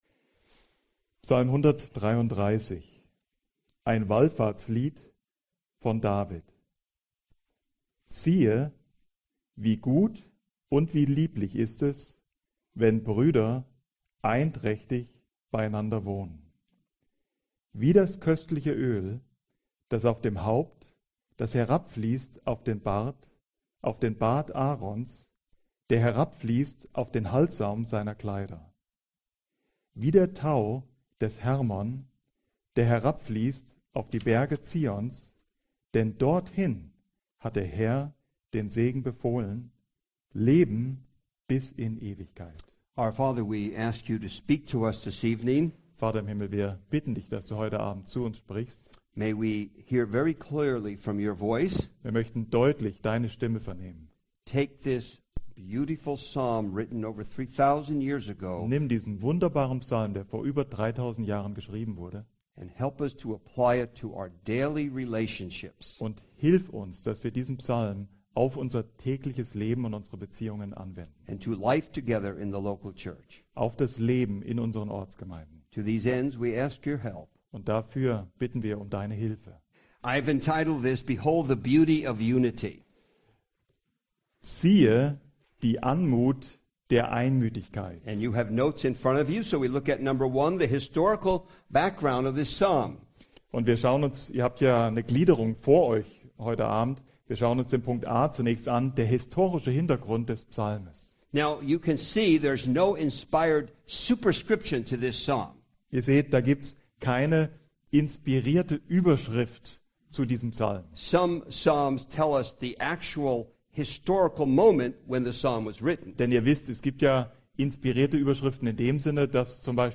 Segen der Einheit (Audio-Vortrag)
Inhalt Predigt/Vortrag